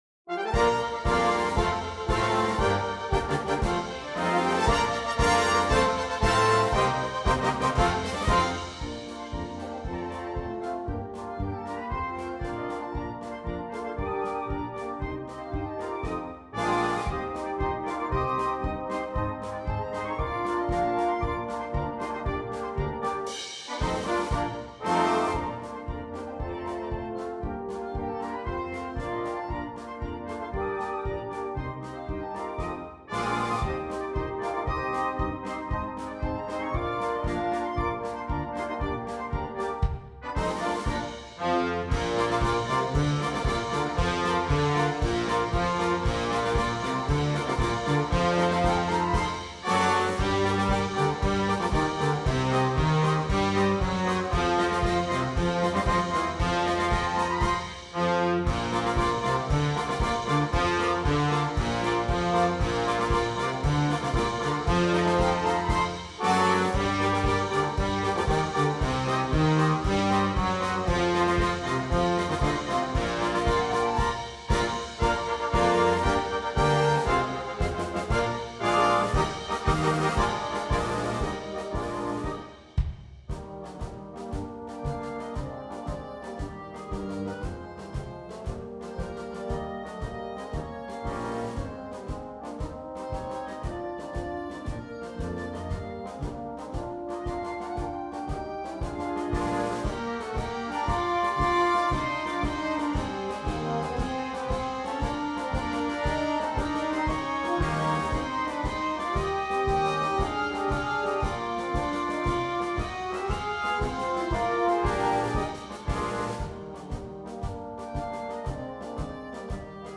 Banda completa
Marchas